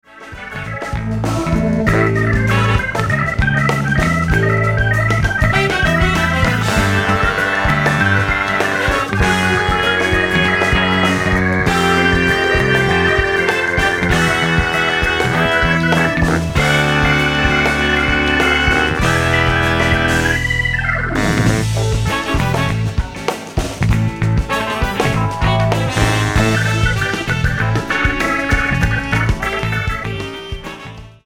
98 BPM